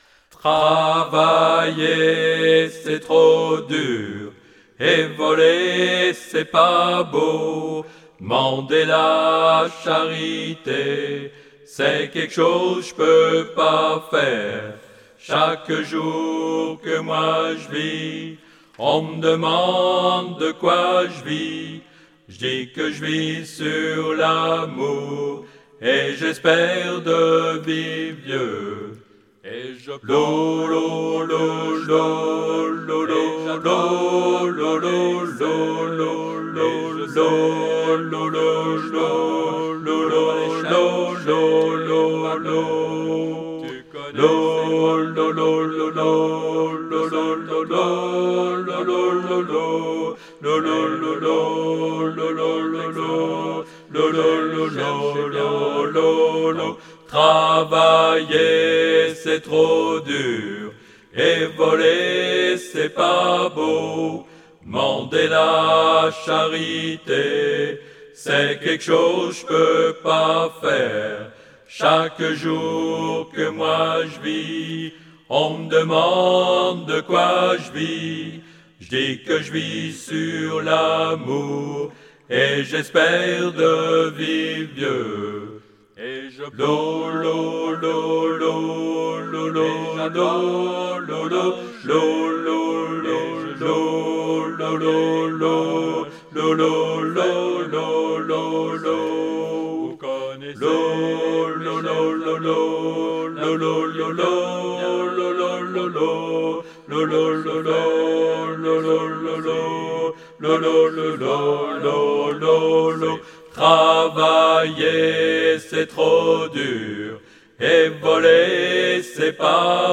traditionnel cajun
Alto